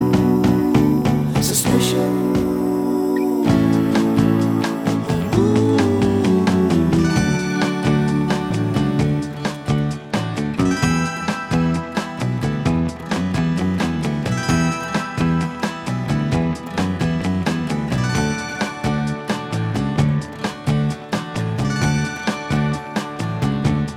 Minus Guitars Pop (1960s) 2:42 Buy £1.50